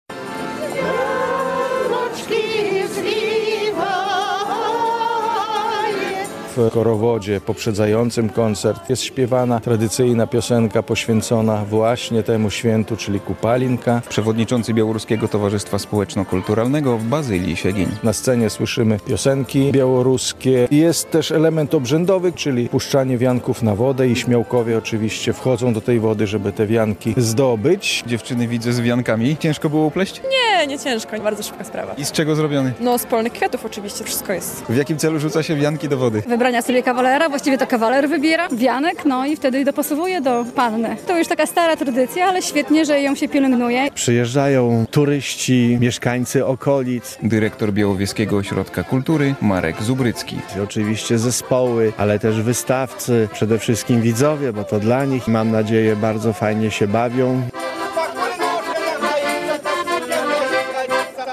Był korowód, łowienie wianków, pokaz fajerwerków i koncerty białoruskich zespołów z Podlasia. W Białowieży odbyło się Kupalle, czyli tradycyjna Noc Kupały.